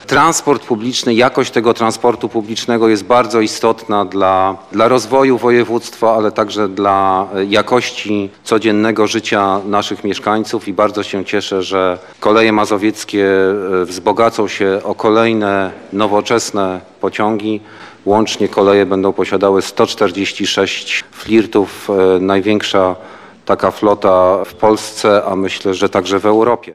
– mówił Ludwik Rakowski, przewodniczący sejmiku.